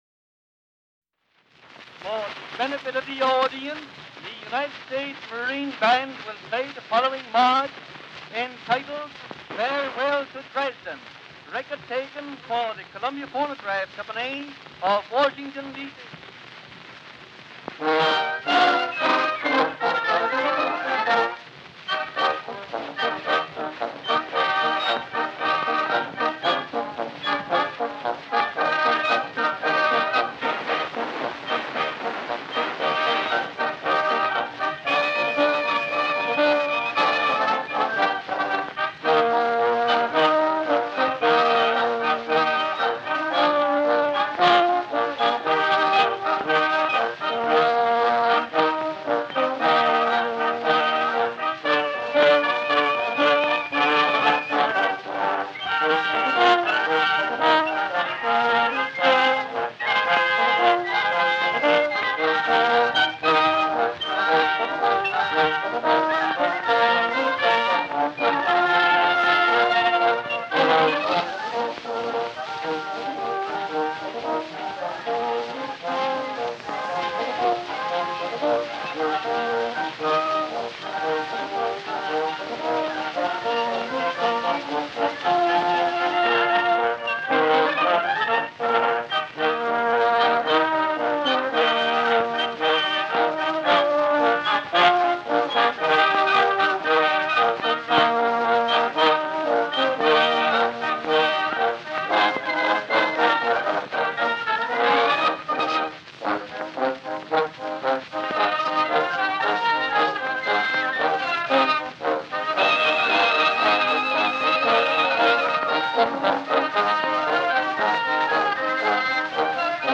Disc 1: Early Acoustic Recordings